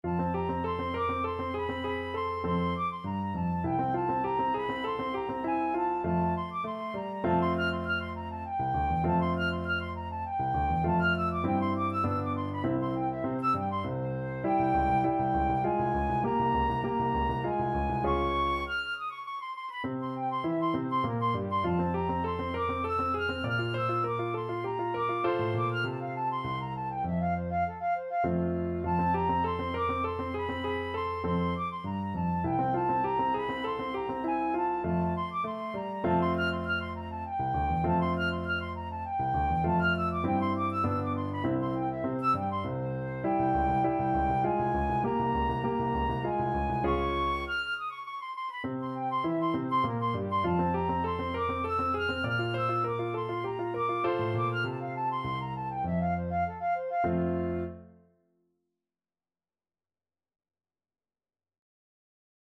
F major (Sounding Pitch) (View more F major Music for Flute )
3/4 (View more 3/4 Music)
Flute  (View more Intermediate Flute Music)
Classical (View more Classical Flute Music)